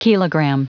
Prononciation du mot kilogram en anglais (fichier audio)
Prononciation du mot : kilogram